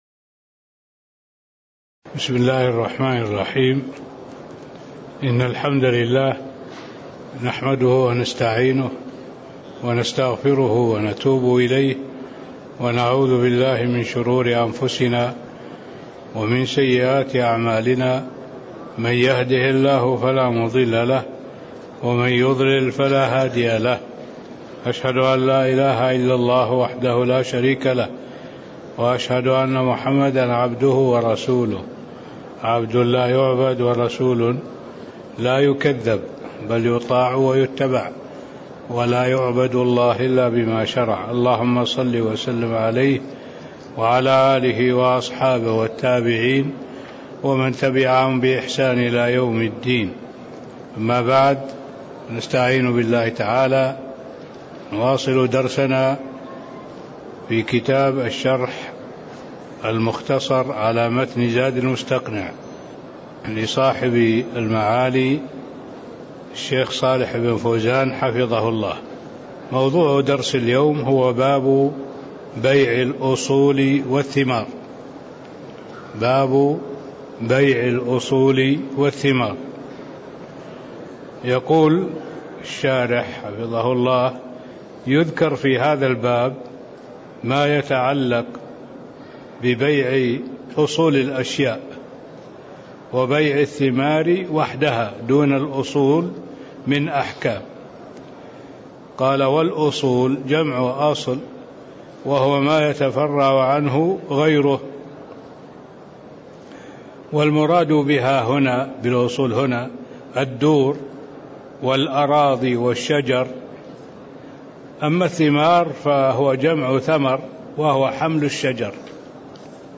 تاريخ النشر ١٩ صفر ١٤٣٥ هـ المكان: المسجد النبوي الشيخ